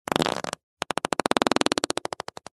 Звуки пердежа
3. Проницательный пук